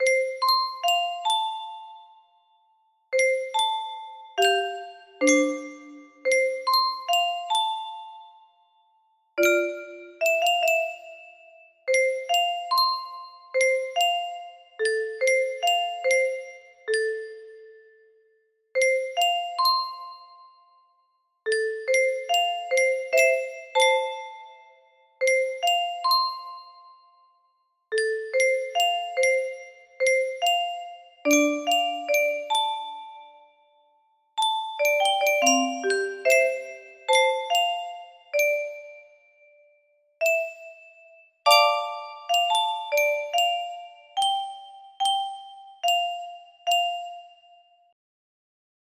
Specially adapted for 20 notes